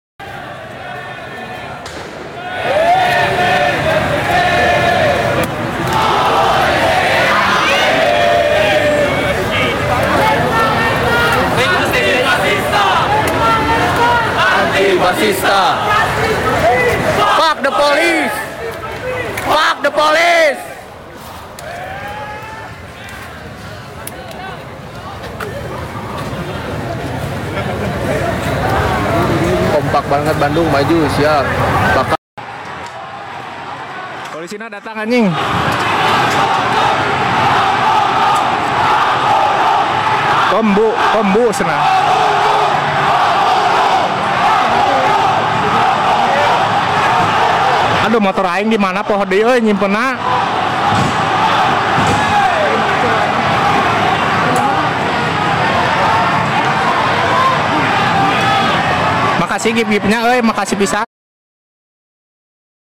demonstrasi di depan gedung DPR sound effects free download